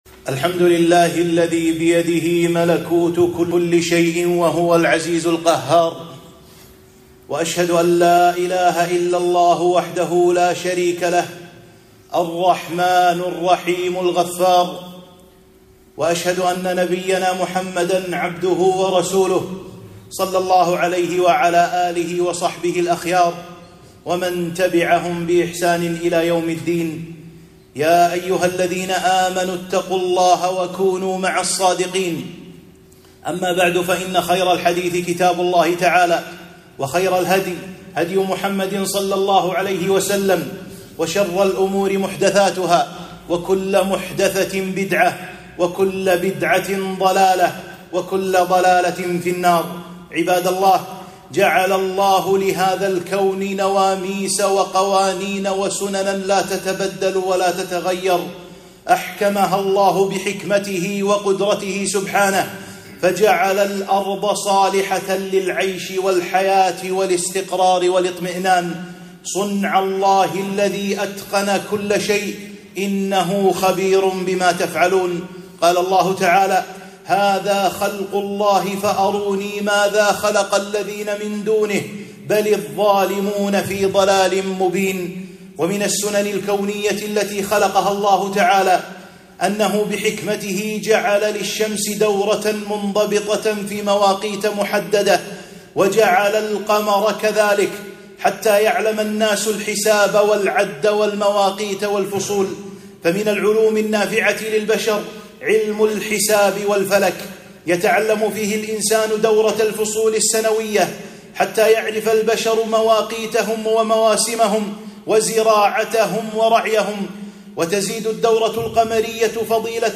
خطبة - حكم الاحتفال بالسنة الجديدة، وتعظيم القرآن الكريم